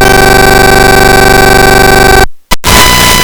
I build a sniffer from a cheap USB sound card, here is the result:
* the packets are ppm modulated (distance coding) with a pulse of ~500 us
weather_sensor.wav